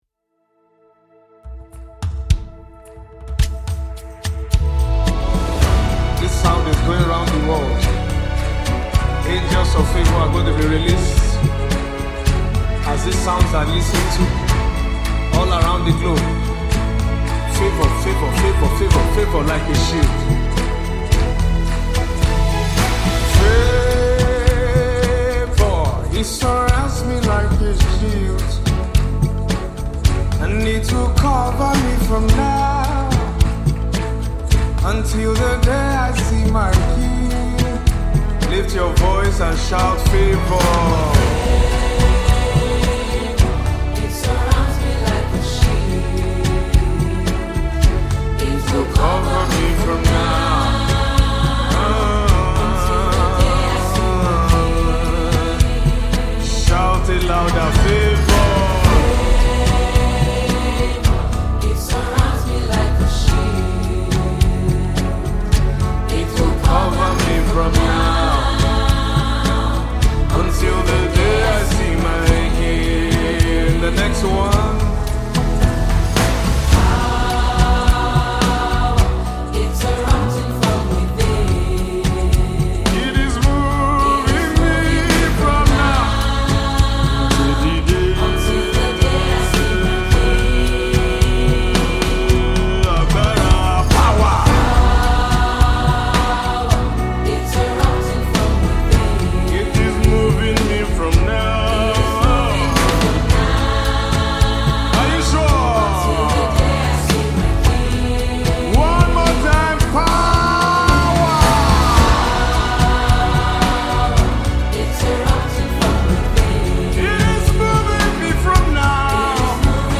African Gospel Music